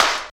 35 CLAP 2.wav